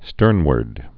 (stûrnwərd)